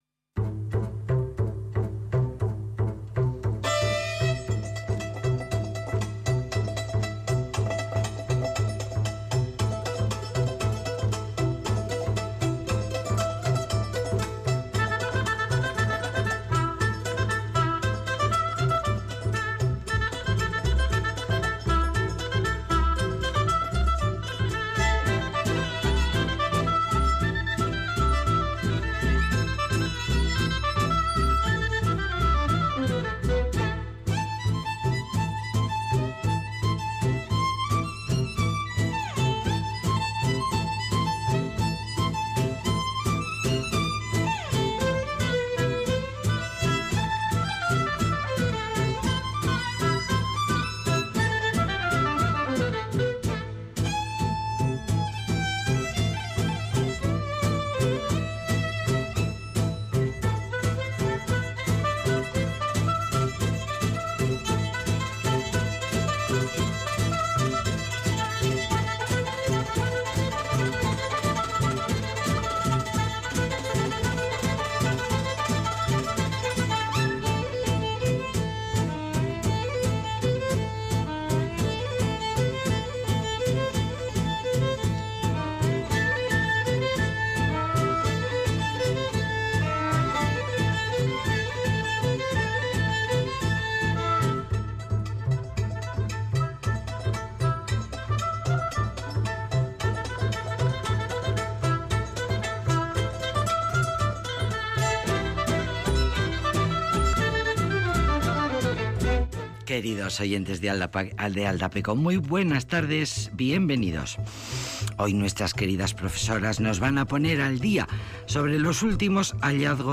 Múisca y entrevistas para la sobremesa